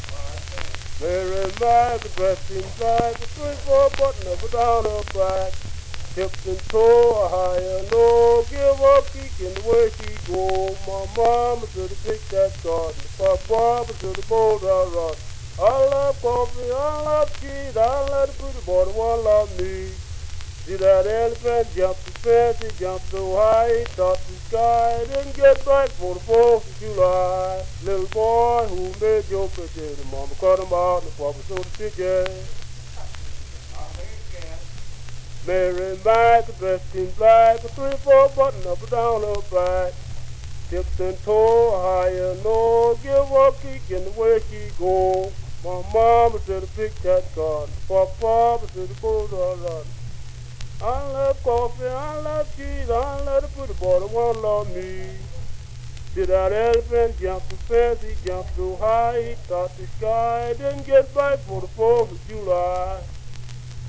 Singing Games
歌唱遊戲
Such was the case when a group of prisoners on the Cummins State Farm in Arkansas sang "Mary Mack." In this version, Mary had only three or four buttons on her back, whereas in the other version she had 24.